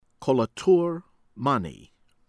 MALIK, YASIN YAH-seen   MAH-lihk